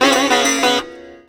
SITAR GRV 07.wav